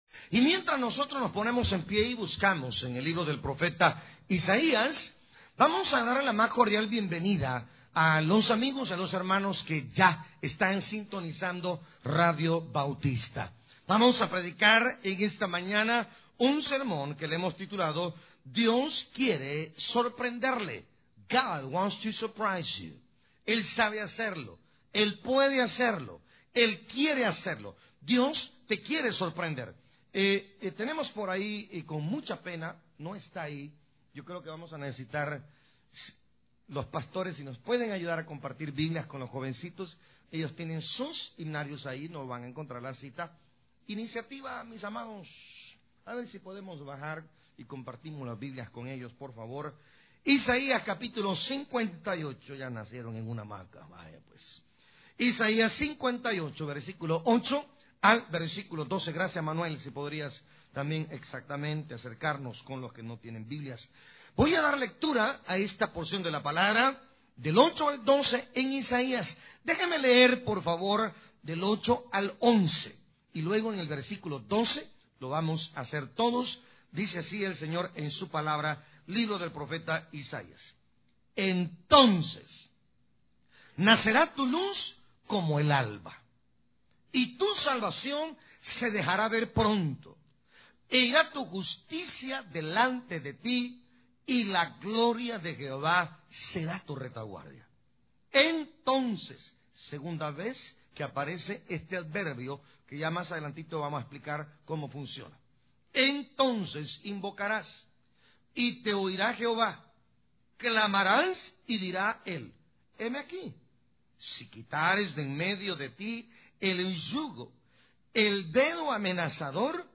Predicador